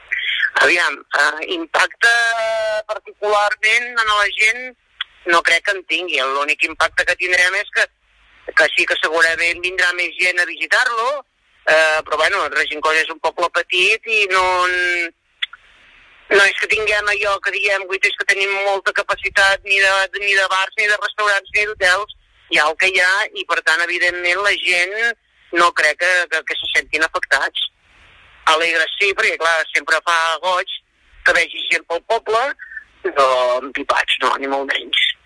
Així ho explica l’alcaldessa del consistori.